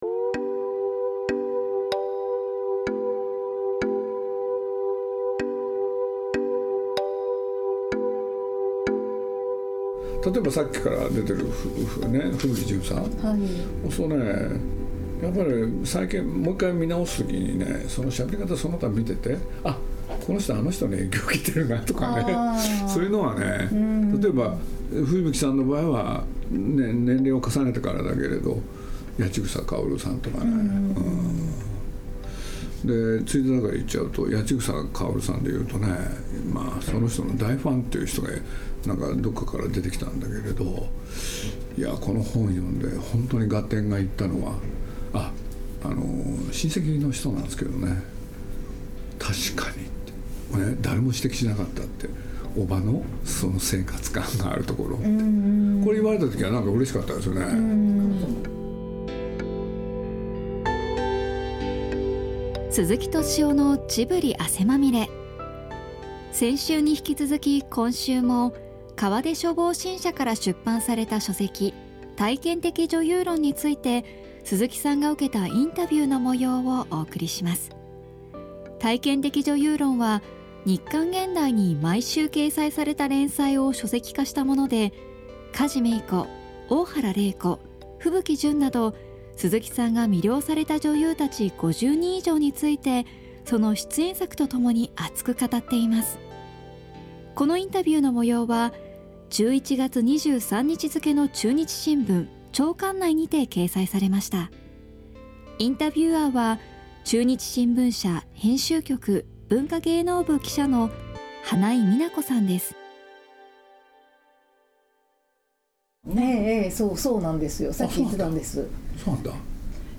ここには夜毎、汗まみれな人が集って映画談義に花を咲かせます。 その模様は、TOKYO FM 80.0MHｚをキーステーションに、JFN系列38のFM局毎週日曜23:00～23:30に『鈴木敏夫のジブリ汗まみれ』の番組で放送中。